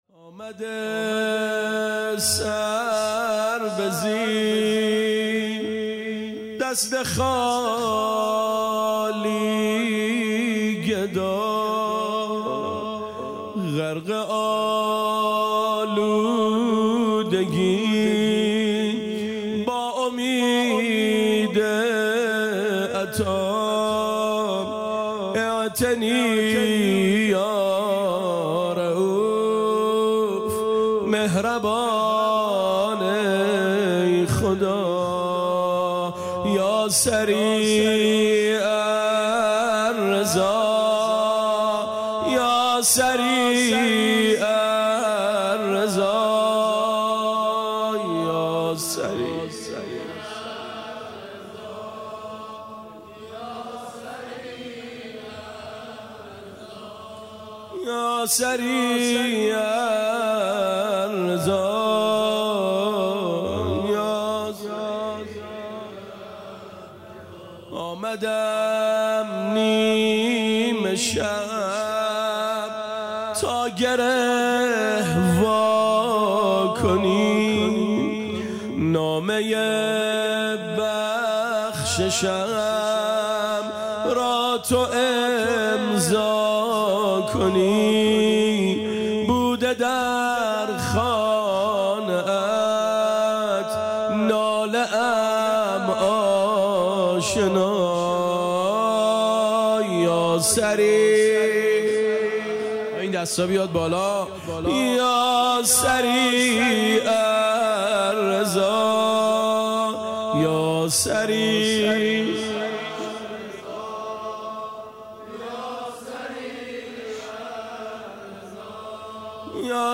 زمزمه
شب ۲۷ م ماه رمضان